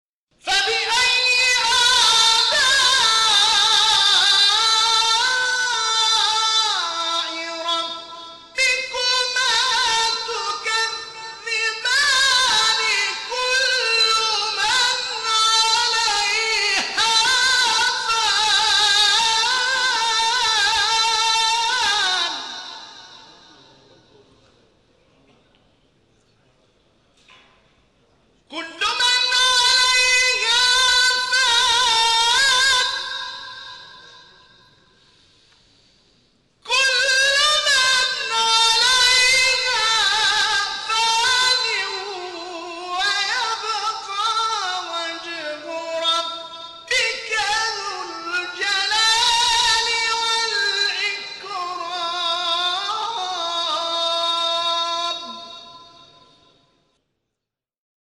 شبکه اجتماعی: نغمات صوتی از تلاوت قاریان برجسته و ممتاز کشور که به‌تازگی در شبکه‌های اجتماعی منتشر شده است، می‌شنوید.